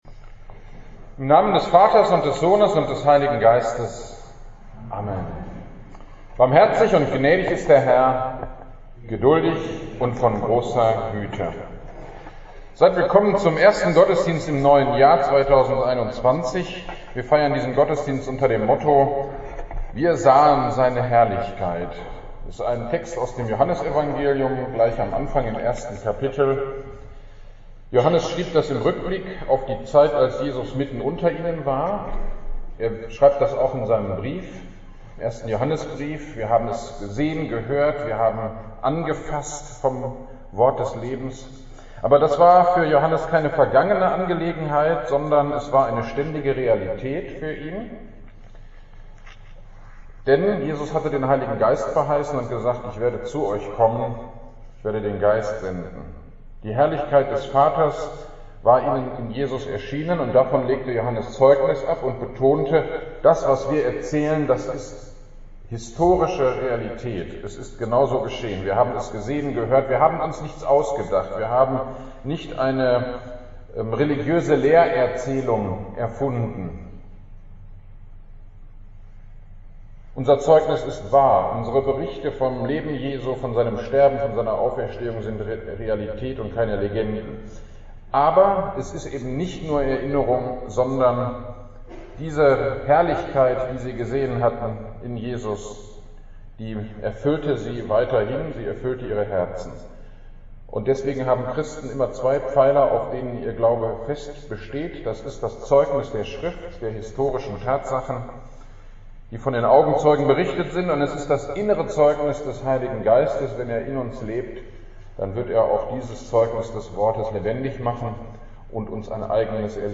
Gottesdienst am 03.01.2021 - Predigt zu Lukas 2,21 - Kirchgemeinde Pölzig